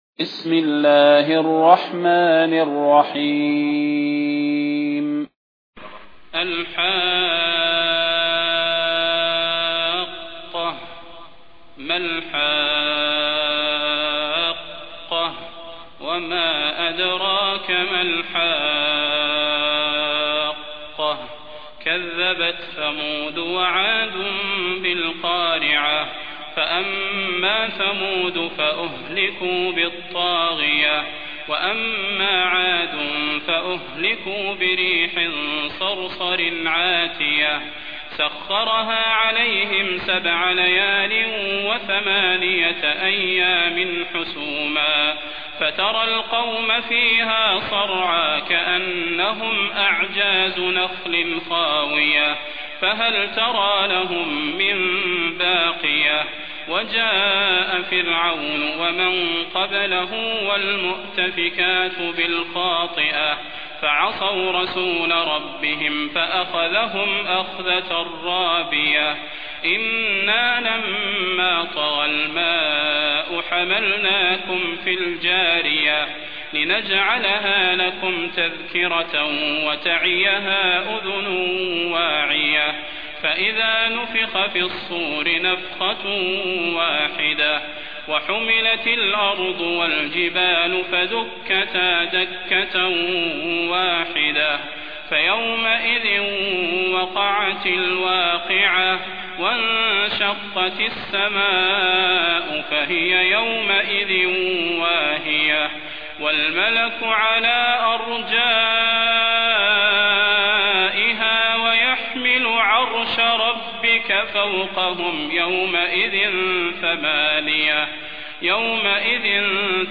المكان: المسجد النبوي الشيخ: فضيلة الشيخ د. صلاح بن محمد البدير فضيلة الشيخ د. صلاح بن محمد البدير الحاقة